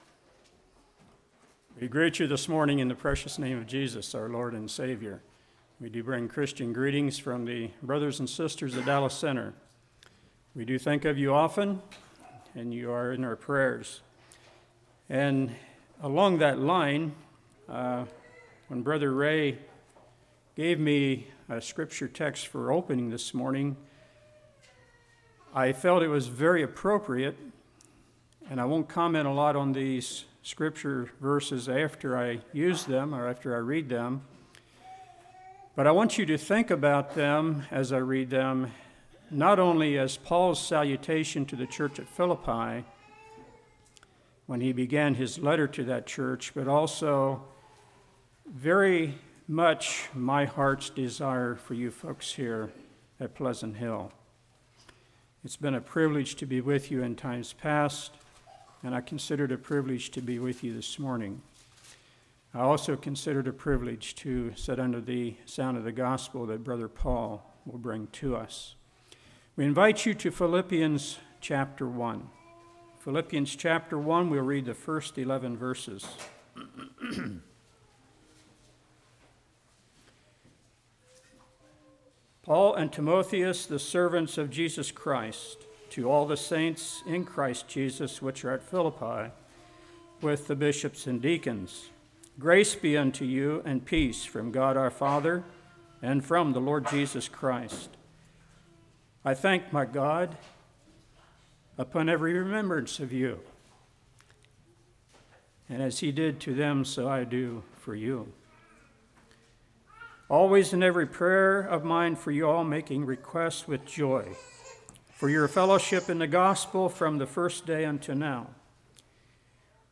Passage: Philippians 1:1-11 Service Type: Morning